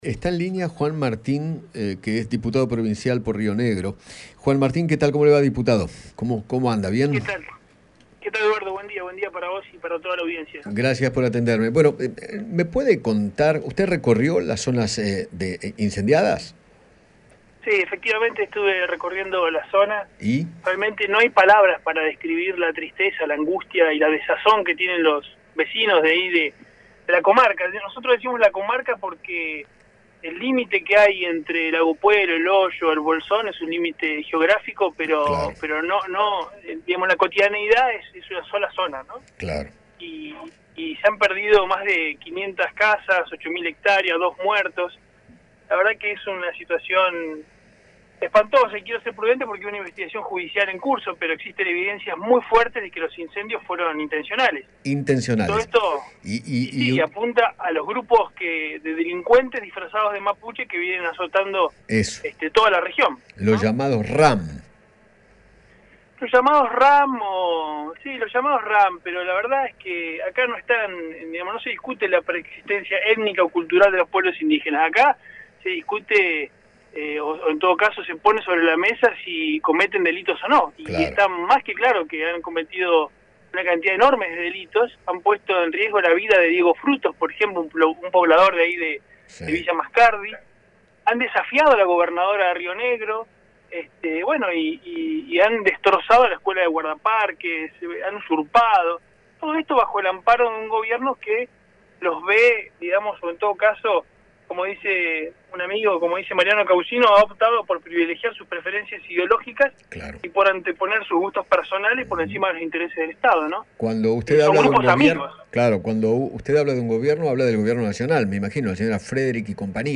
Juan Martin, diputado provincial de Río Negro, dialogó con Eduardo Feinmann sobre los incendios en la Patagonia y apuntó contra los supuestos responsables.